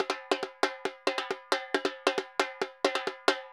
Repique Merengue 136-1.wav